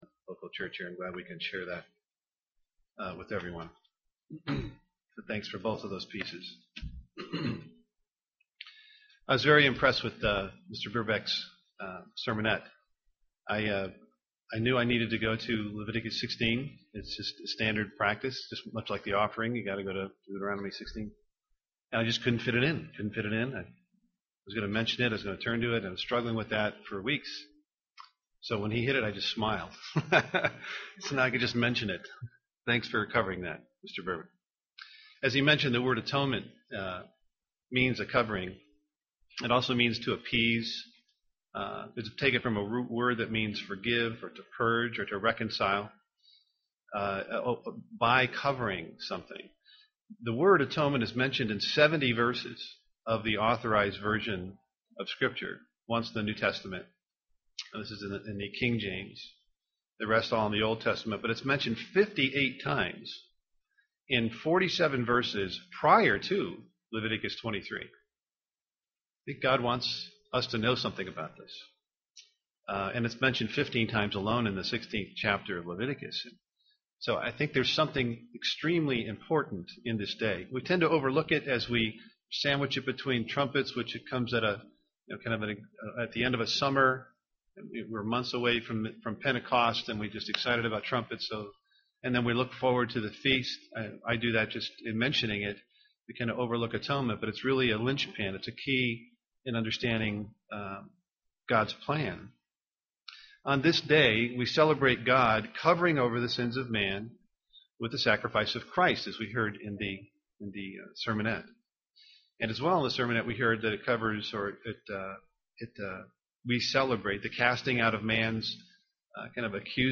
Print How those who enter God’s presence live [Hebrews 10:19-25] UCG Sermon Studying the bible?